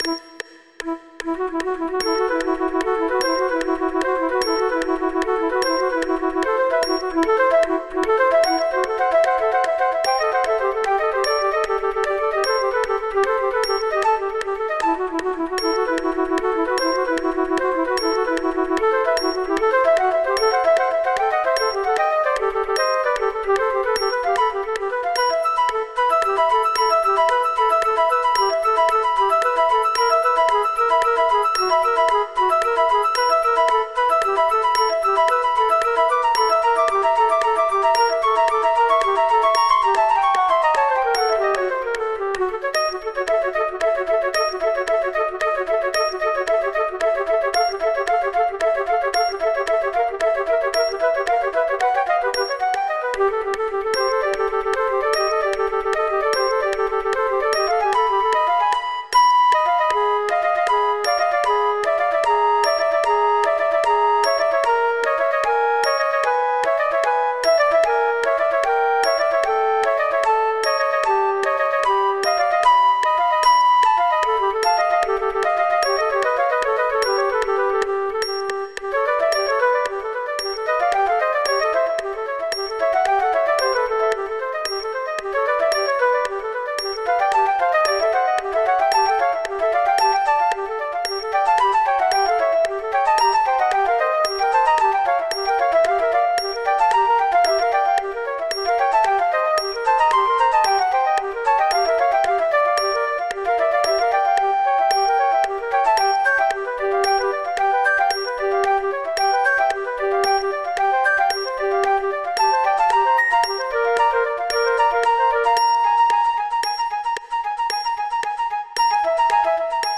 This trio for three flutes is full of aural illusions.
With metronome clicks (and apologies for a strange metronome artefact right at the beginning),
with the second flute missing there are slow,
full speed,